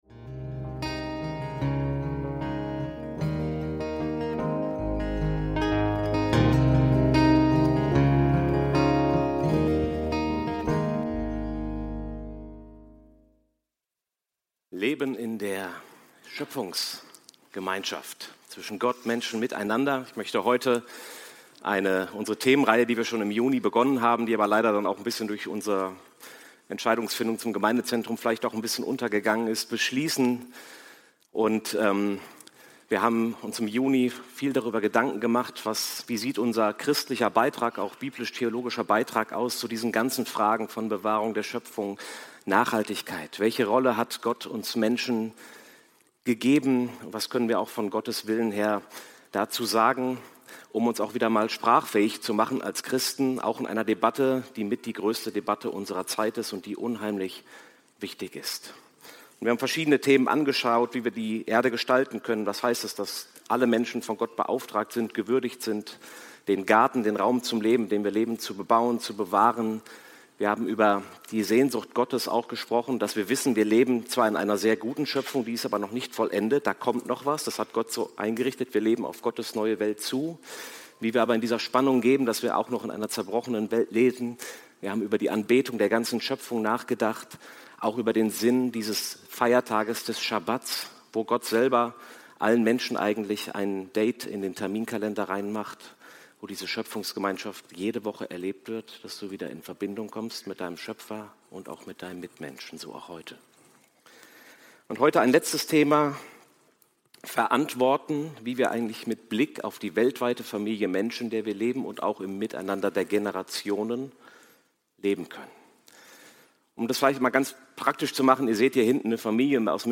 Verantworten – Leben in der Schöpfungsgemeinschaft – Predigt vom 31.08.2025